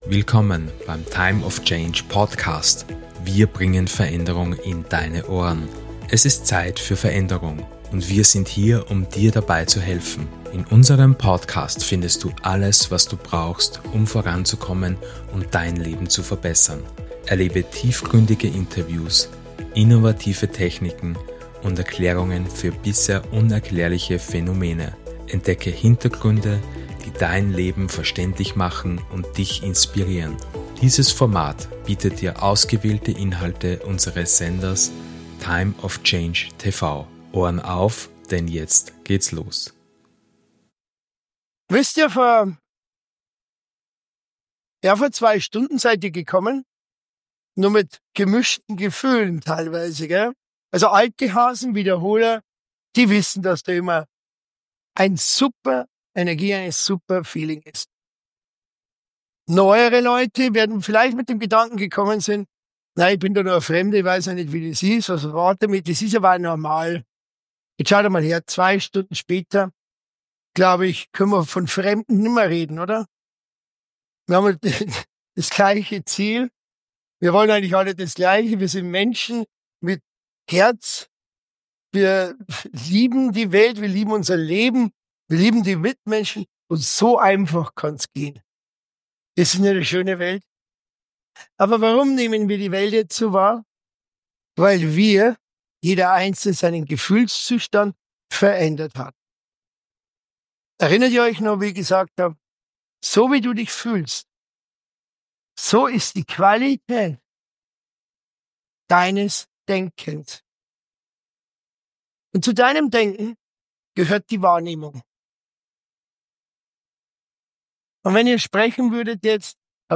Willkommen zu Teil 3 des Days of Change Seminarwochenende ’Der Gefühlslalchemist’! In dieser dritten Stunde erforschen wir gemeinsam, wie Du Dein Leben durch Dein Gefühl aktiv gestalten kannst.